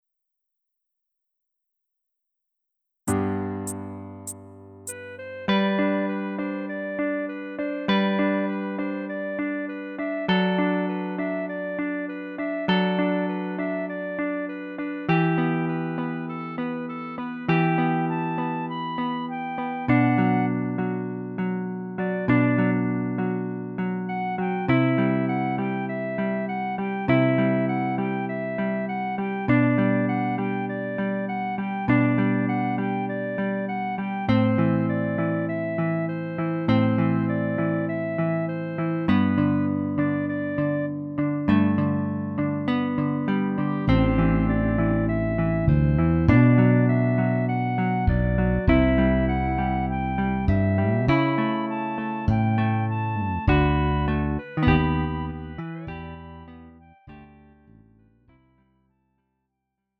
음정 원키 3:23
장르 가요 구분 Lite MR